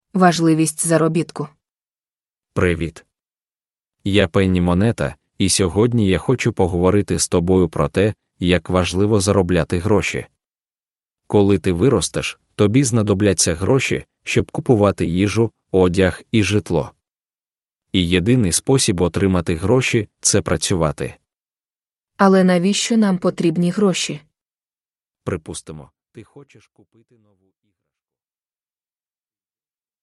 An AI narrated short financial story for children explaining the importance of earning money.